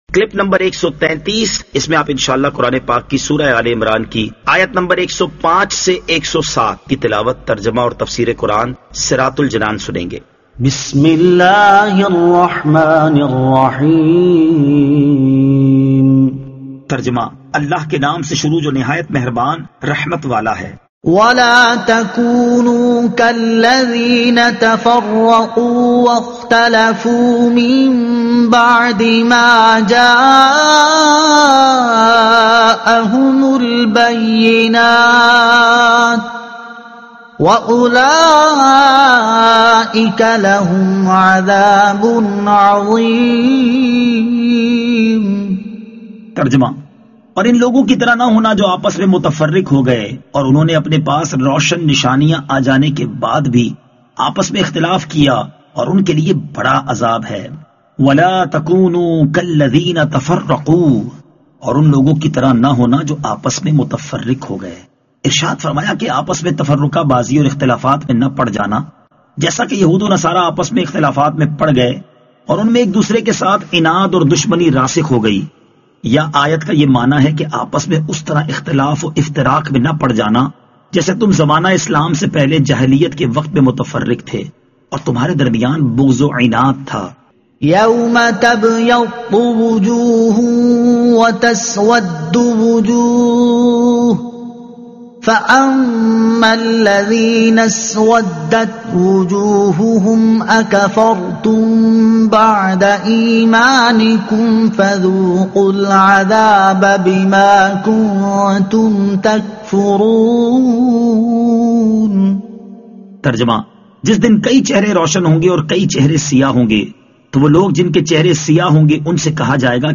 Surah Aal-e-Imran Ayat 105 To 107 Tilawat , Tarjuma , Tafseer